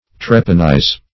Search Result for " trepanize" : The Collaborative International Dictionary of English v.0.48: Trepanize \Trep"an*ize\, v. t. To trepan.